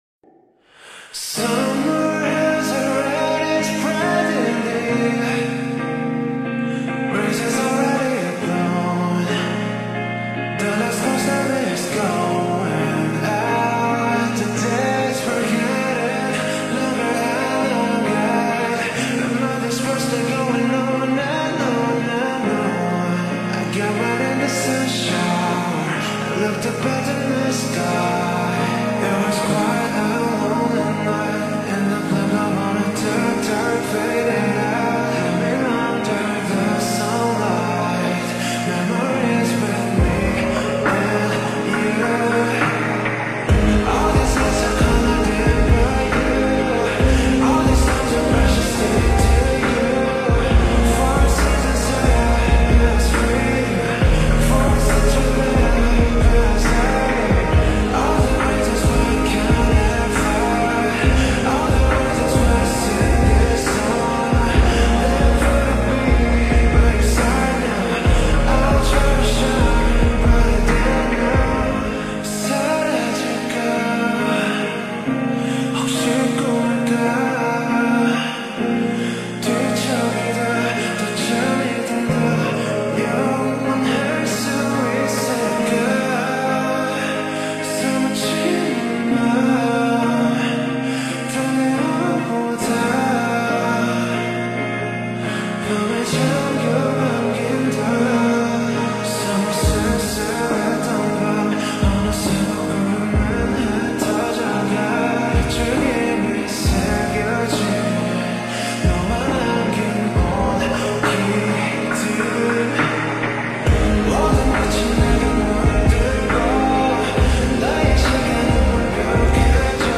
ورژن اسلو 1
slowed + reverb